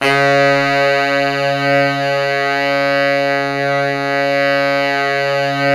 SAX SFC#3E0C.wav